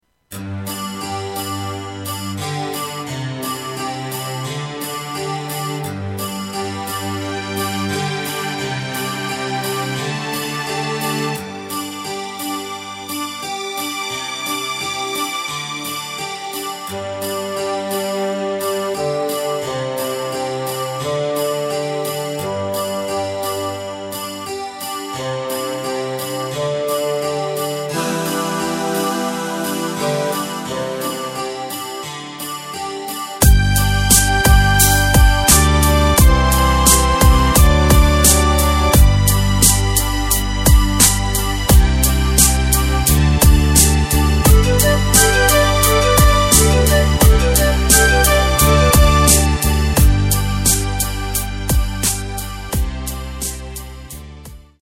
Takt:          4/4
Tempo:         87.00
Tonart:            G
Schlager Neuaufnahme aus dem Jahre 2012!
Playback mp3 Demo